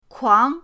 kuang2.mp3